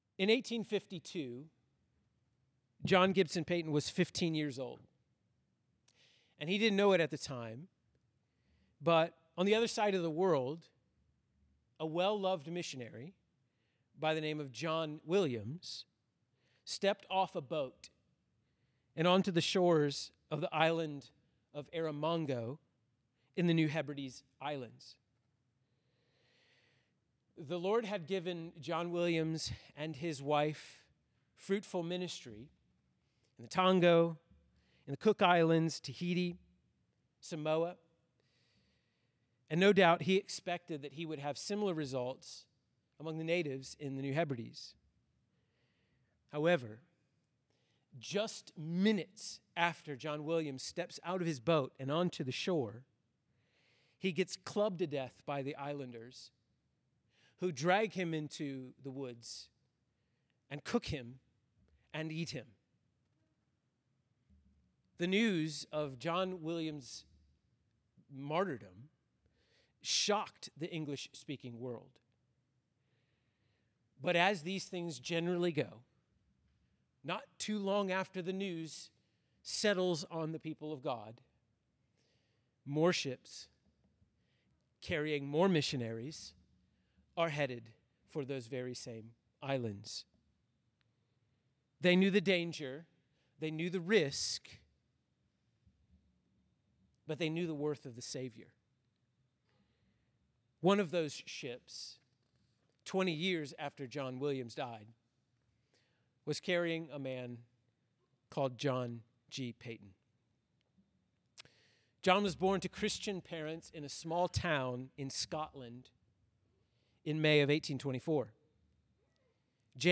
A SERIES OF BIOGRAPHICAL LECTURES ON MEN AND WOMEN THE LORD HAS USED TO DISPLAY THE EXCELLENCIES OF CHRIST.